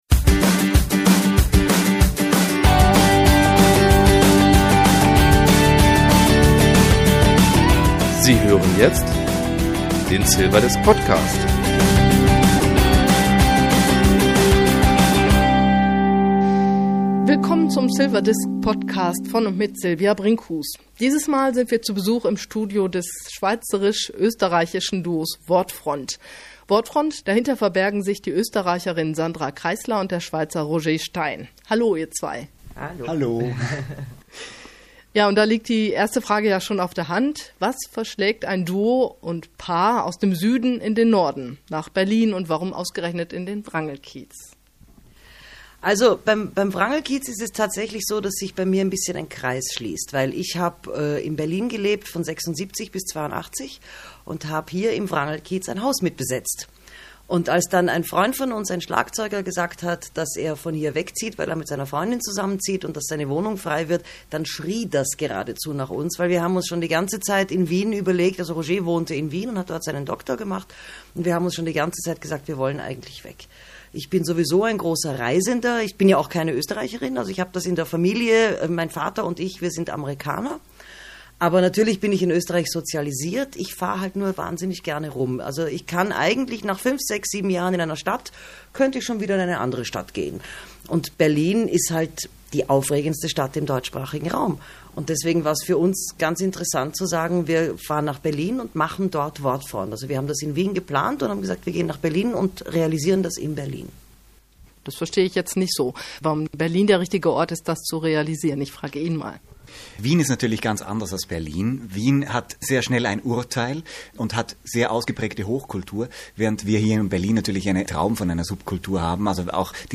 Interview mit Wortfront